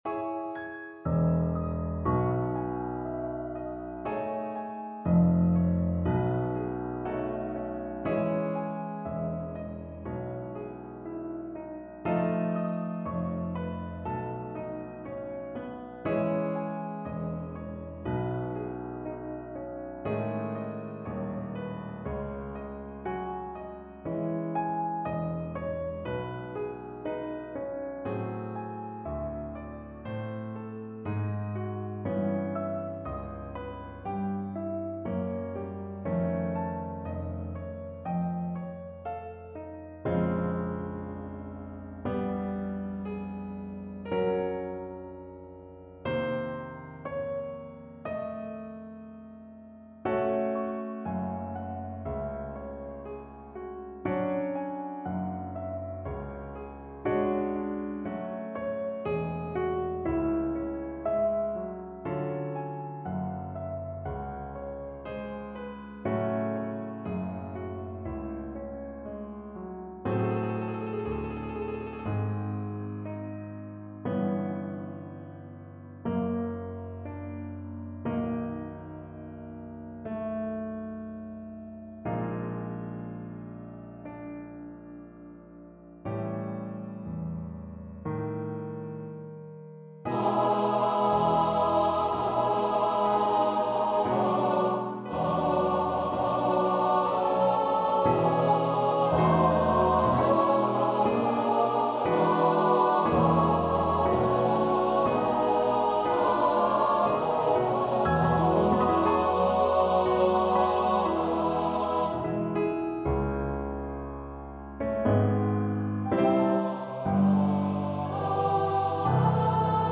Instrument: Choir
Style: Classical